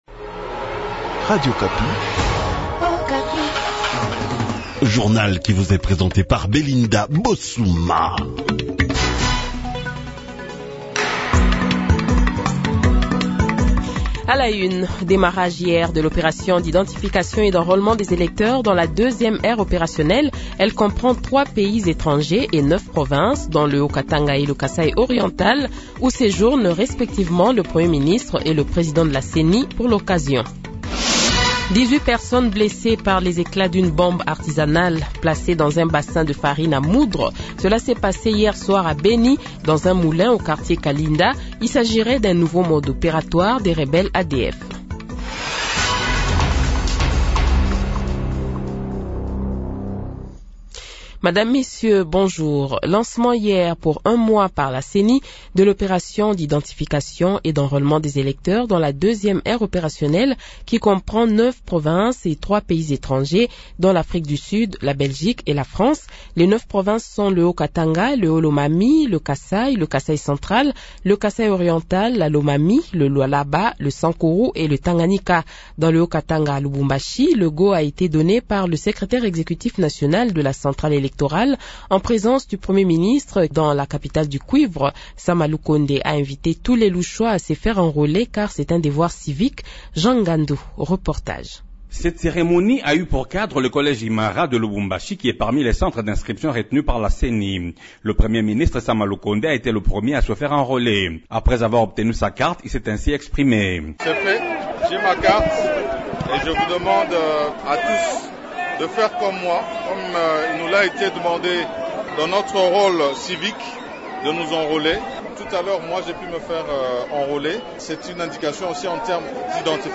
Le Journal de 7h, 26 Janvier 2023 :